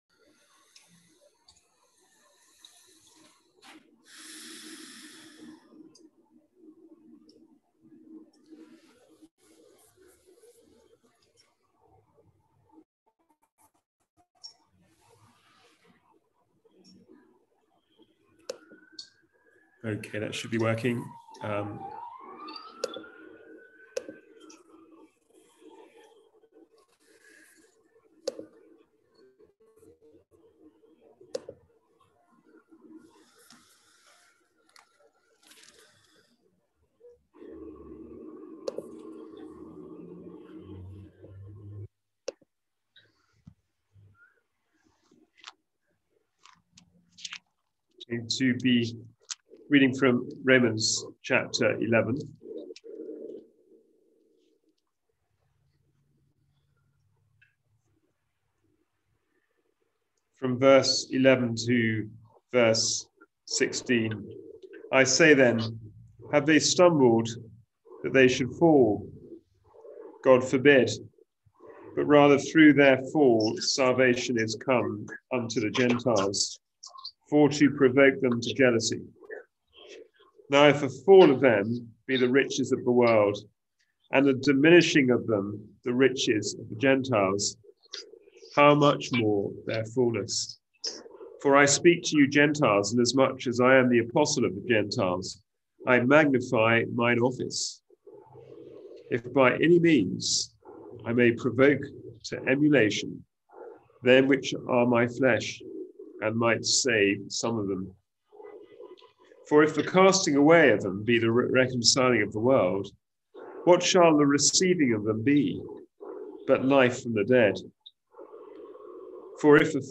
Passage: Romans 11:14 Service Type: Wednesday Bible Study